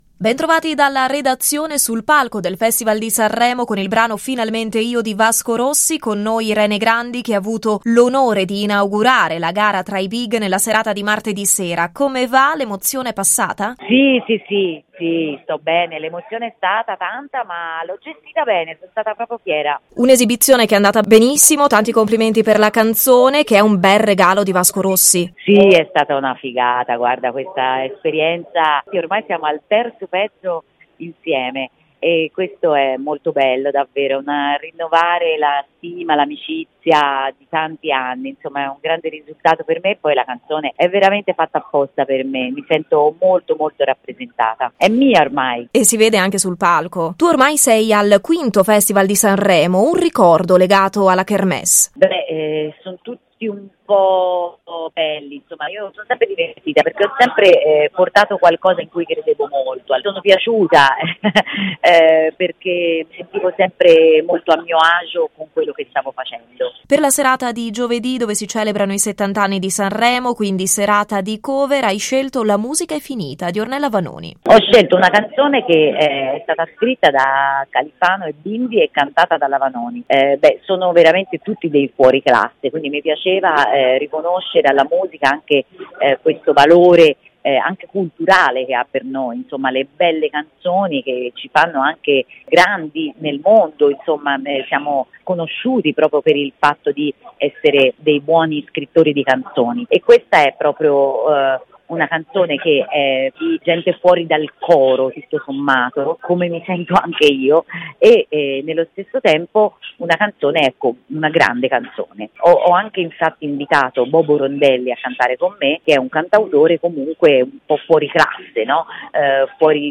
SANREMO 2020: RADIO PICO INTERVISTA IRENE GRANDI